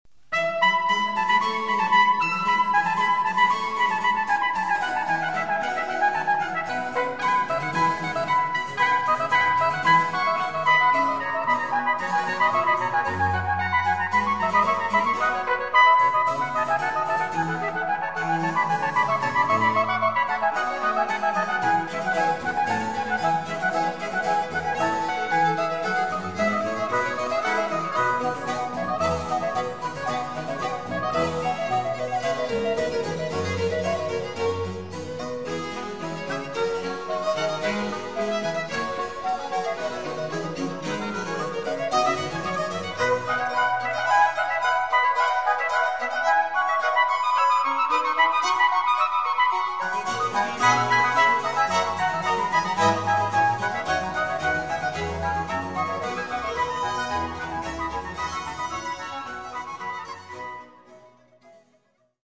Tallinna Barokkorkester
barokktrompet